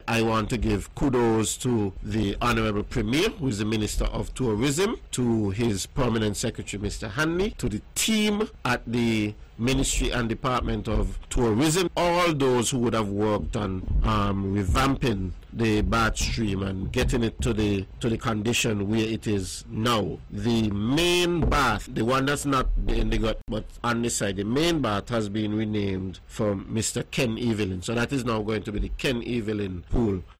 Minister of Youth and Sports, Hon. Troy Liburd hosted Wednesday night’s airing of the “On The Mark” show on VON Radio where he spoke of the revamping of Nevis’ Bath Hot Springs.
Minister Troy Liburd.